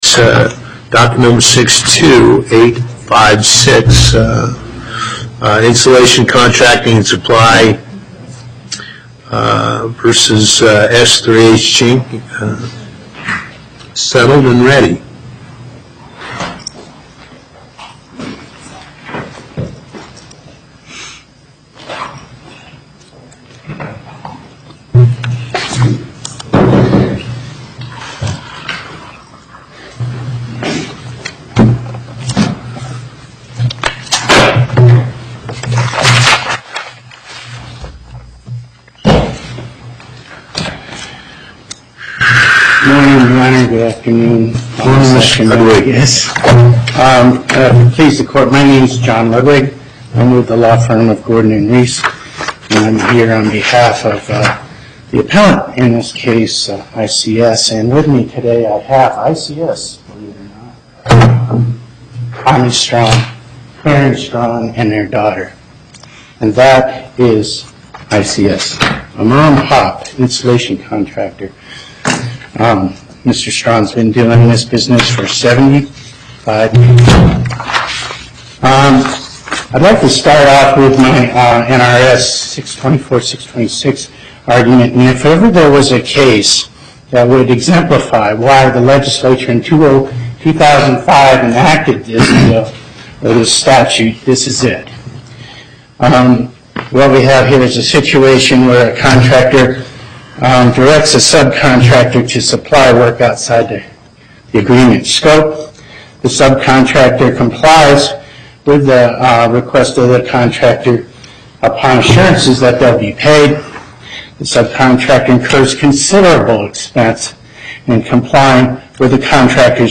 Location: Las Vegas Before the Southern Nevada Panel, Justice Parraguirre Presiding
as counsel for Appellant/Cross-Respondent